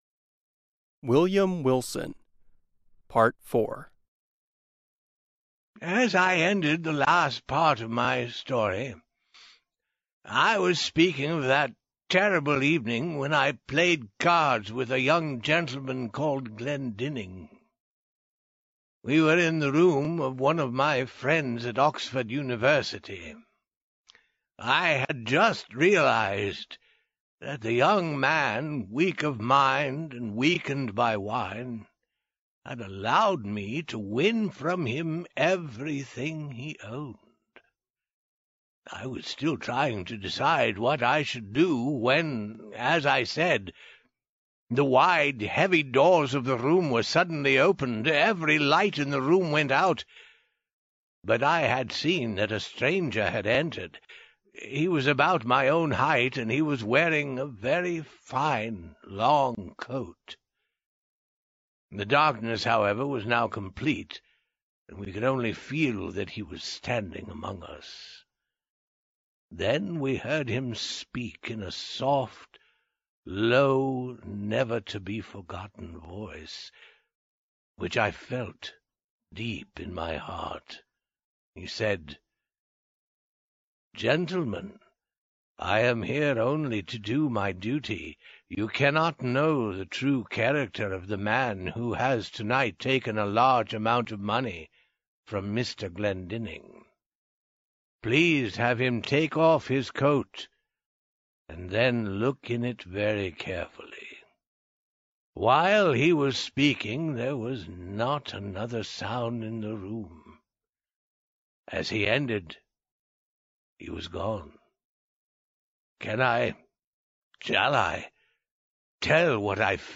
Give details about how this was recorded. We present the last of four parts of the short story "William Wilson," by Edgar Allan Poe. The story was originally adapted and recorded by the U.S. Department of State.